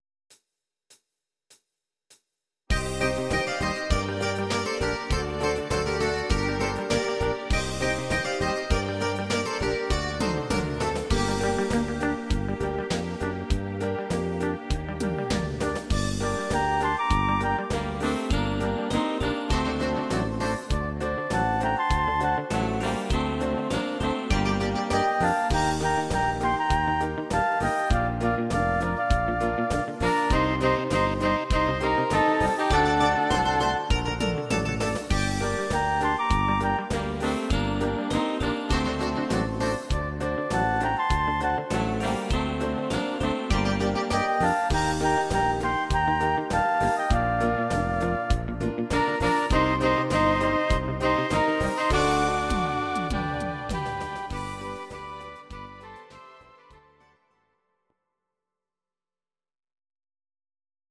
Audio Recordings based on Midi-files
German, Duets, Traditional/Folk, Volkstï¿½mlich